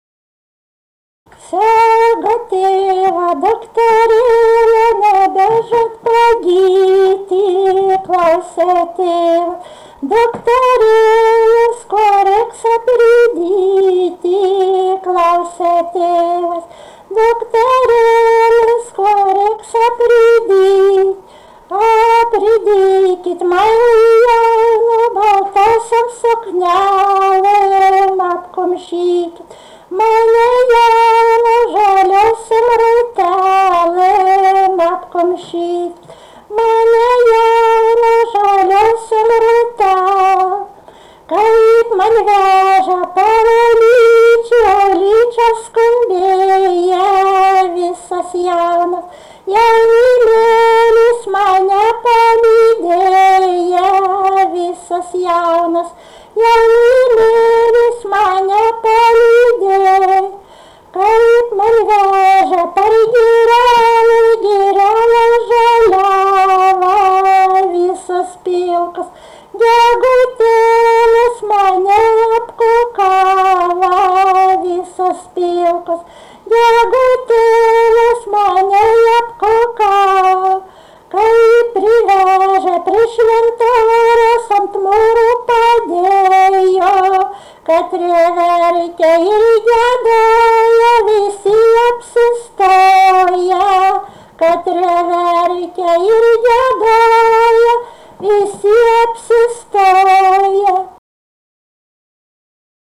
daina, šeimos
vokalinis